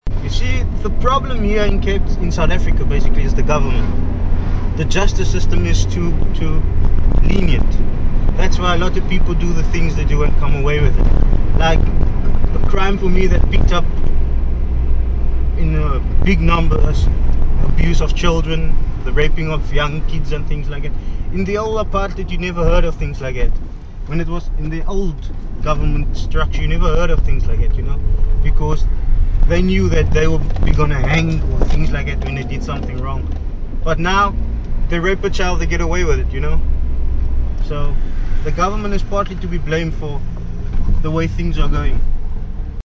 Taxi tales - Cape Town taxi drivers speak about feminism
During their stay, they spoke with taxi drivers about feminism, women in politics and violence against women.